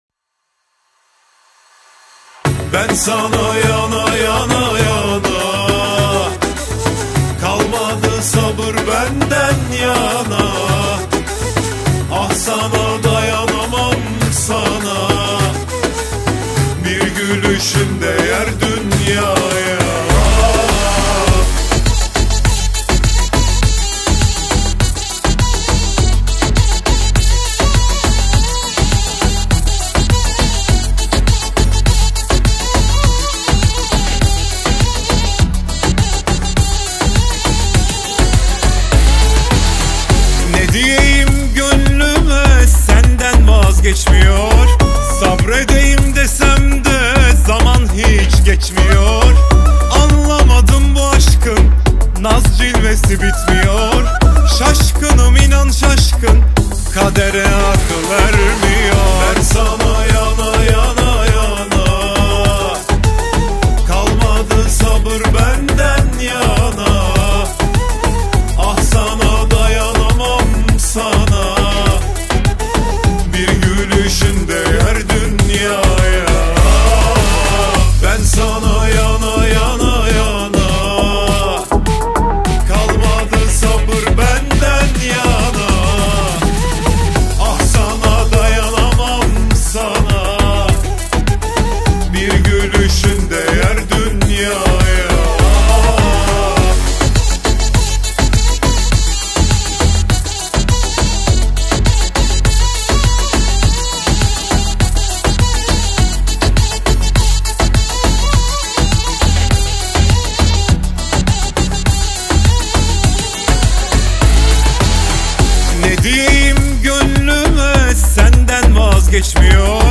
• Категория: Турецкие песни